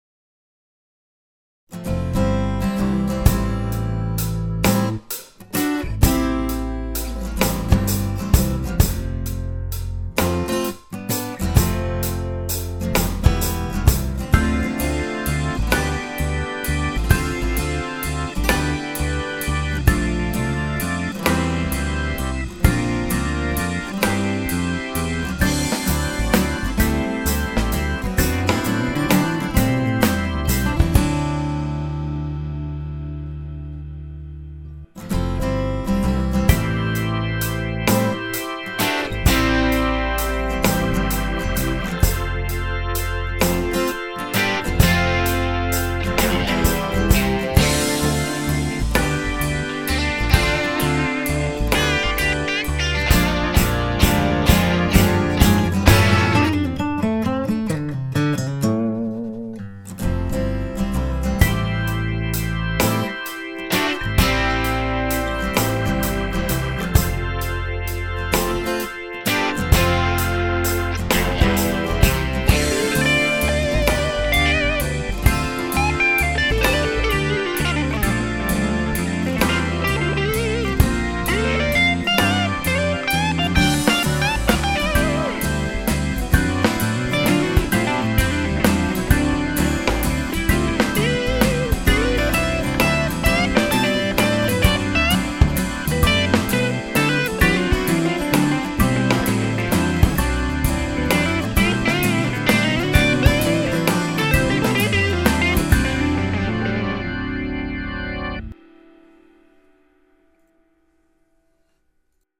AKG TLII on acoustic and Shure SM57 on electrics. Everything is through the Redd preamp, Pultec and La2a (very little compression and eq).